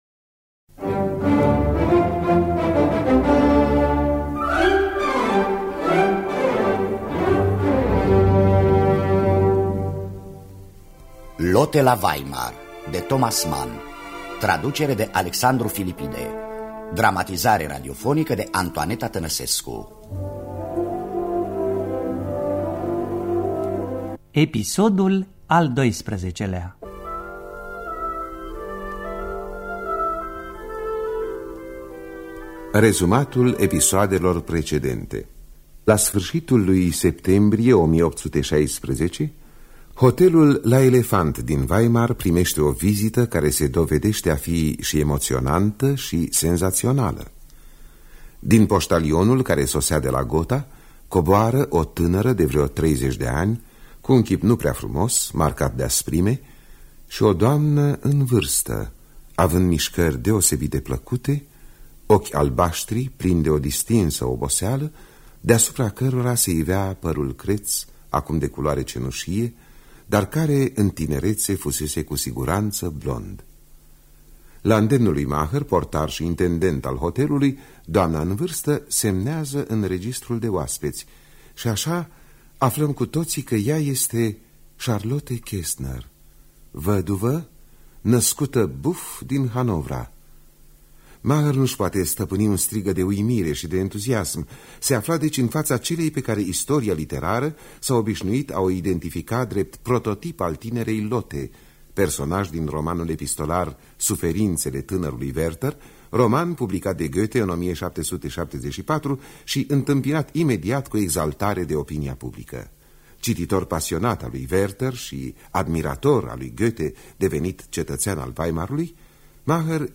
Dramatizarea radiofonică de Antoaneta Tănăsescu.